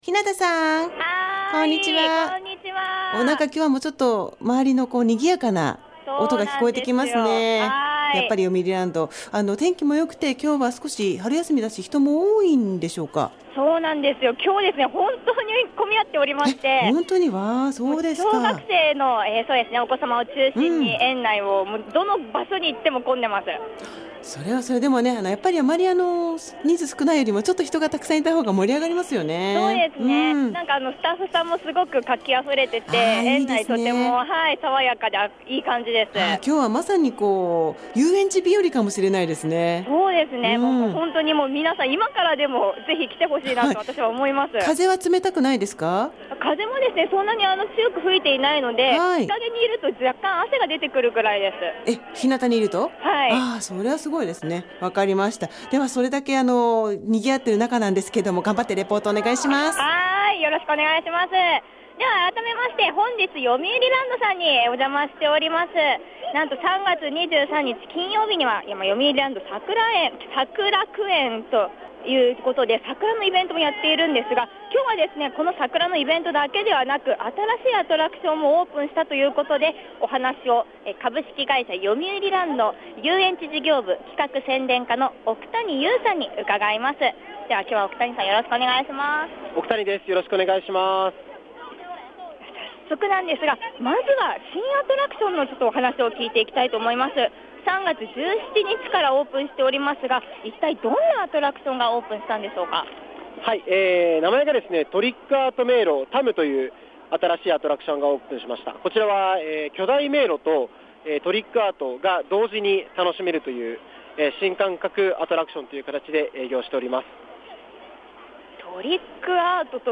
街角レポート
★2011年度入園者数、100万人突破目前の「よみうりランド」さんにお邪魔しました★